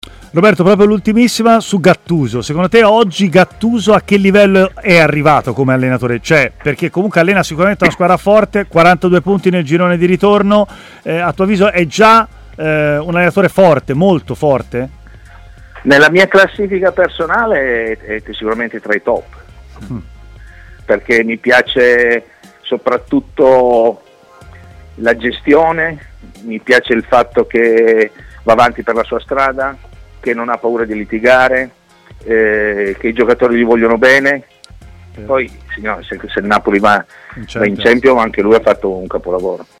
L'ex difensore Roberto Cravero, oggi commentatore tv, ha parlato a Stadio Aperto, trasmissione di TMW Radio